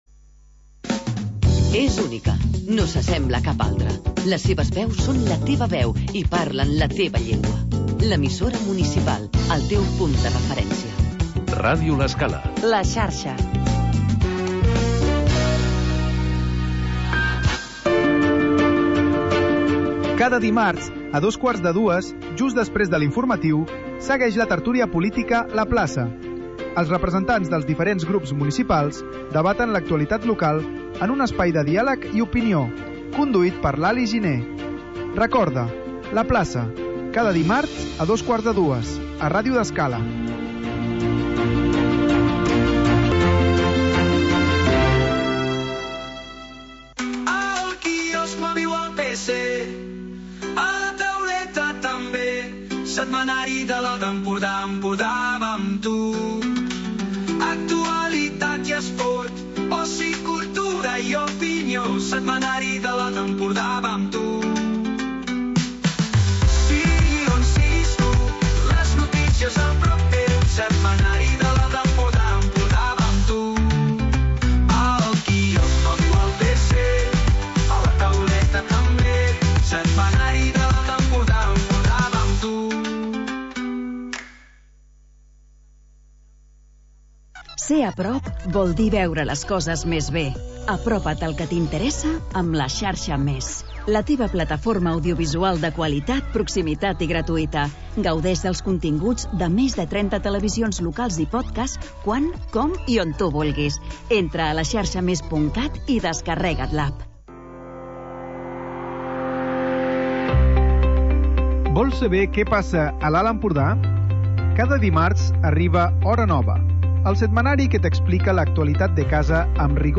Magazín d'entreteniment